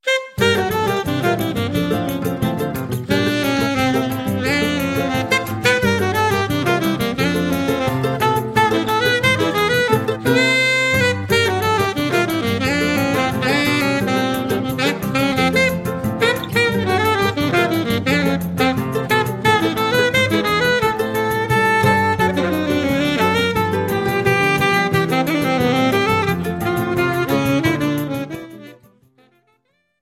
alto saxophone